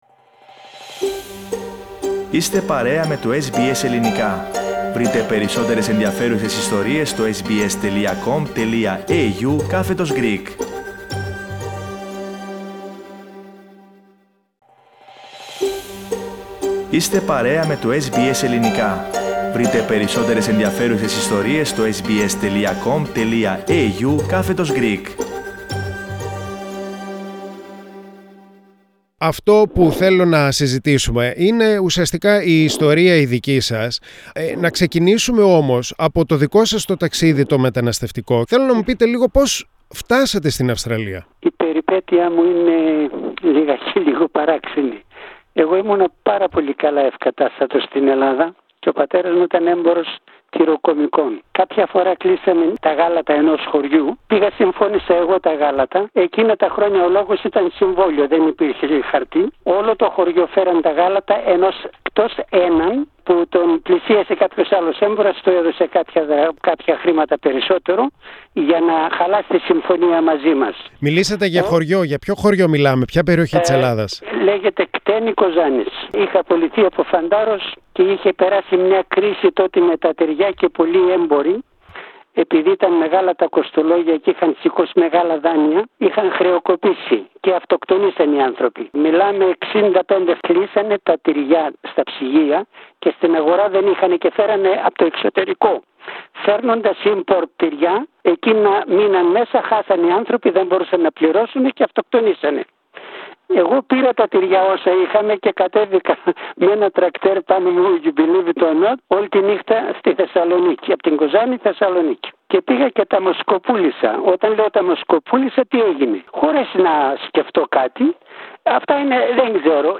does not go unnoticed with his long beard and the calm tone of his voice.